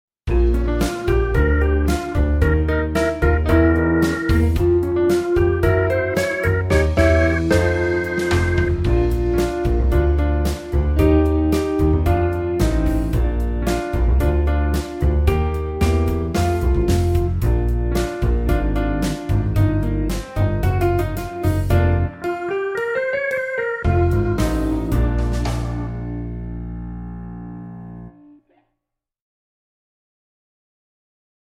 Track 2 Bluesy B